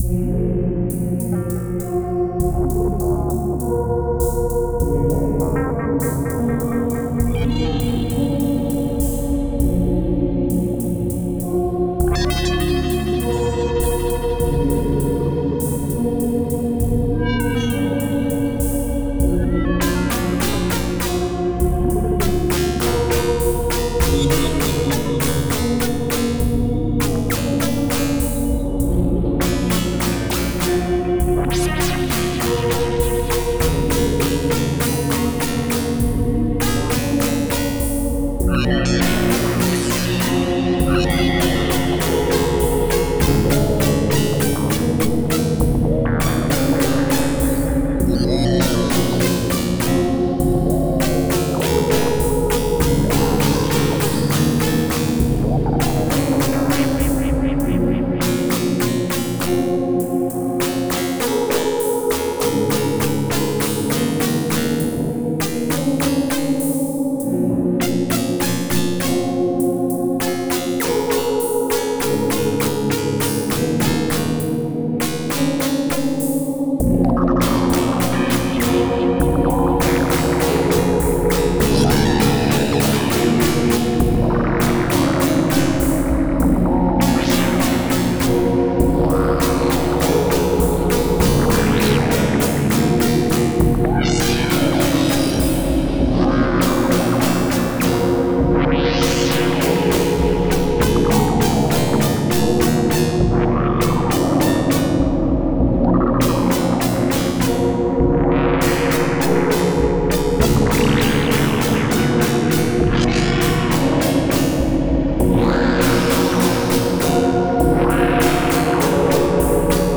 Genre Electronica